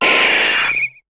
Cri de Roucarnage dans Pokémon HOME.